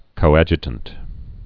(kō-ăjə-tənt)